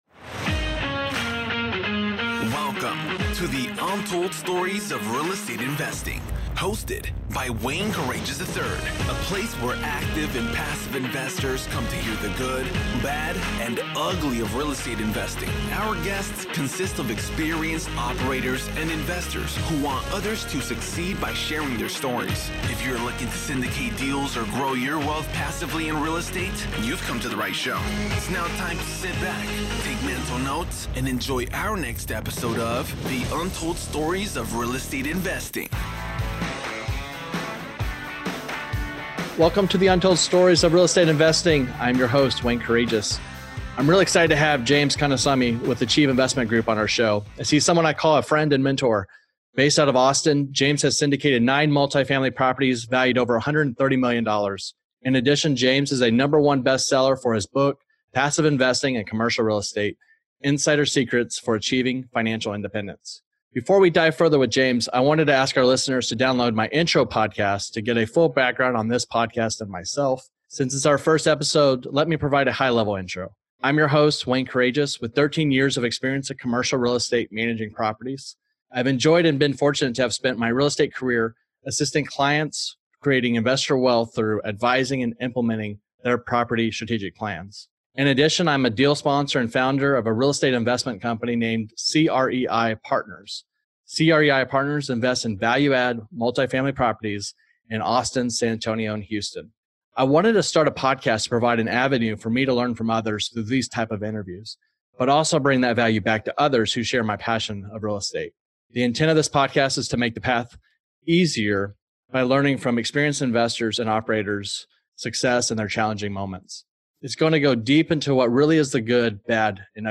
Get ready to hear active and passive investors share their stories about the good, bad, and ugly aspects of real estate investing.